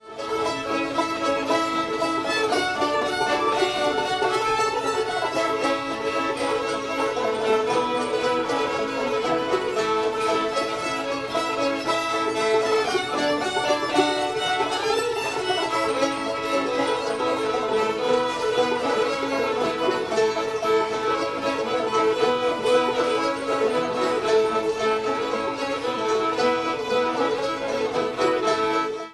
wish i had my time again [A]